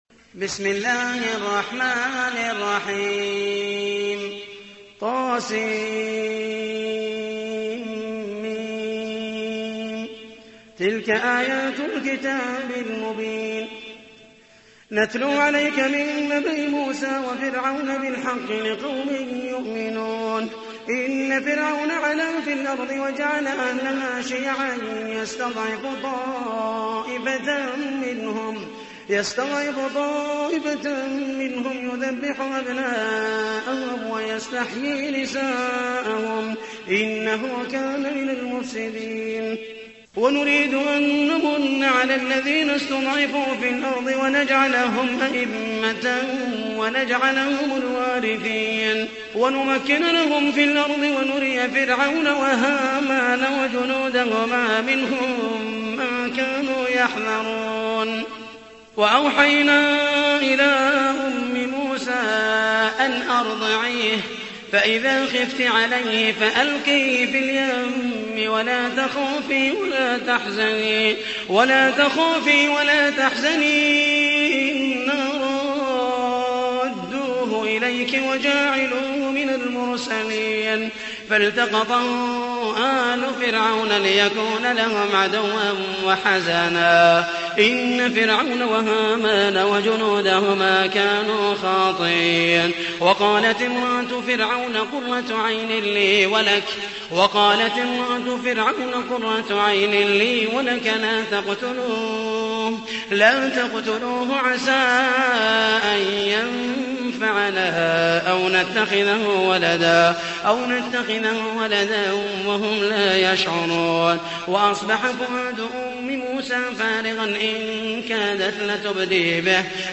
تحميل : 28. سورة القصص / القارئ محمد المحيسني / القرآن الكريم / موقع يا حسين